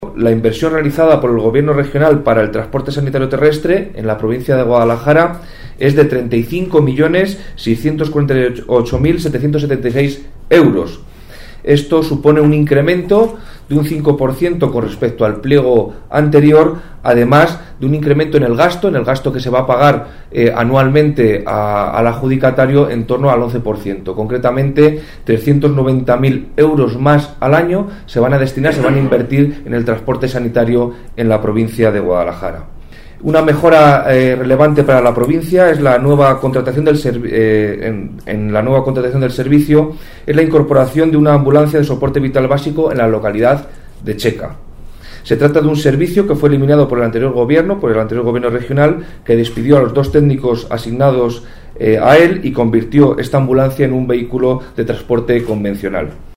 El delegado de la Junta en Guadalajara, Alberto Rojo, habla de las mejoras para la provincia incluidas en el nuevo concurso del transporte sanitario terrestre